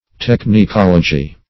Technicology \Tech`ni*col"o*gy\, n.